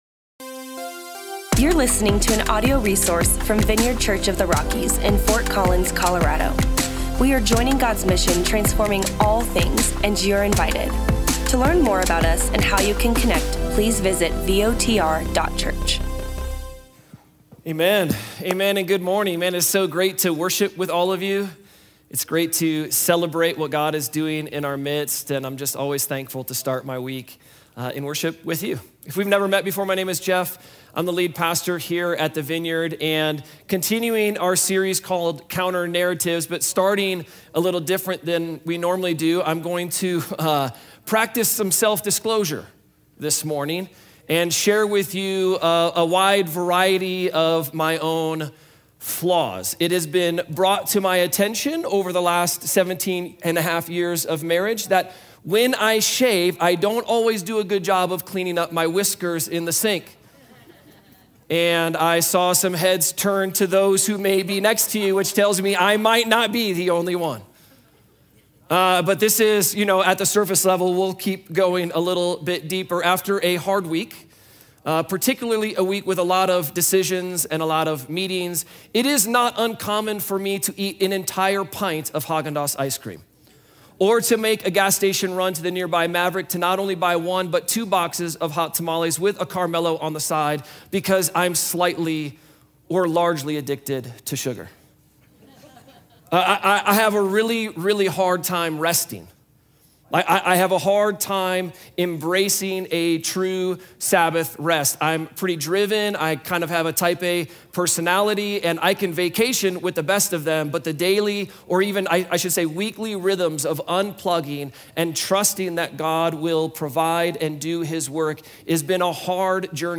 Nobody loves being weak but scripture makes it clear: human weakness is the prelude to God’s power. Listen in as we continue our series, fighting against the cultural norms of our day, with a message that encourages total dependence on God.